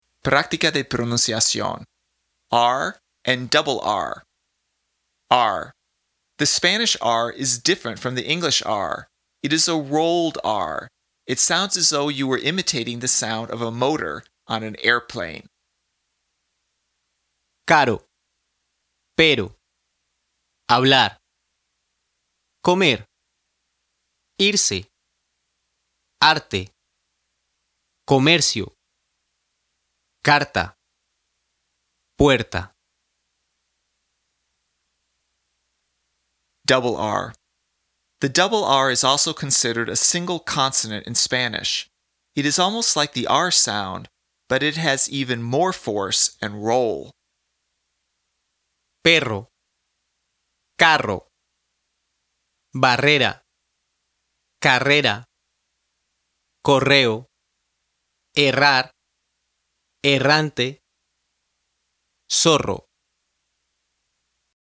PRÁCTICA DE PRONUNCIACIÓN
The Spanish “r” is different from the English “r.” It is a “rolled” r . . . it sounds as though you were imitating the sound of a motor of an airplane.
It is almost like the “r” sound, but it has even more force and roll.